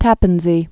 (tăpən zē)